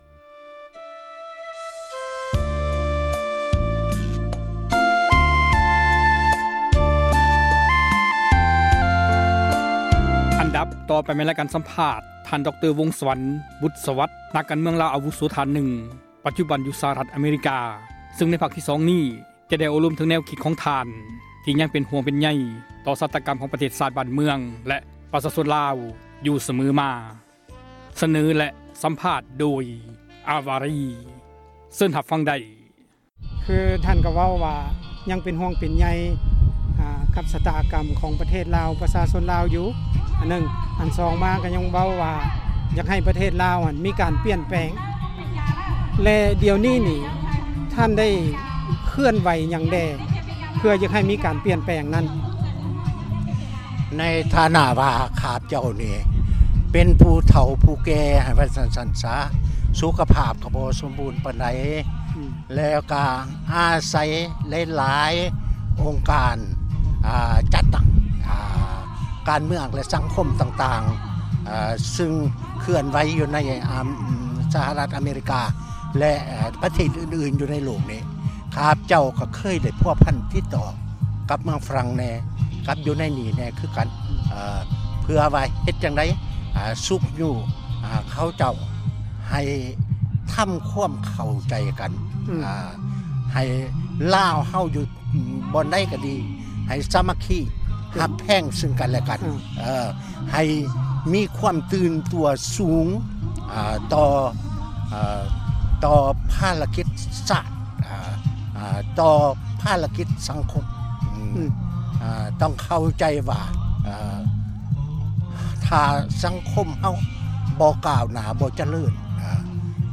ສຳພາດ ດຣ.ບຸດສວັດ(ຕໍ່)